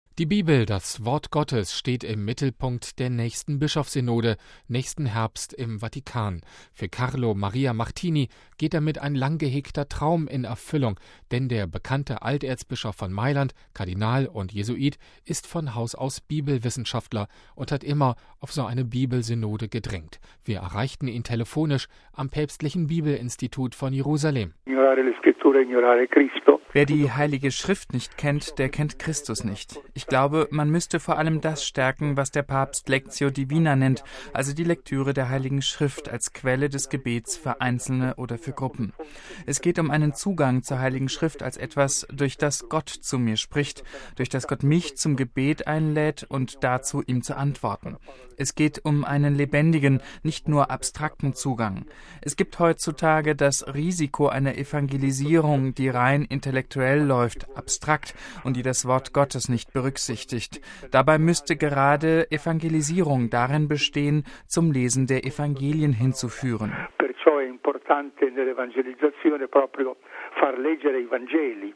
Wir erreichten ihn telefonisch am Päpstlichen Bibelinstitut von Jerusalem.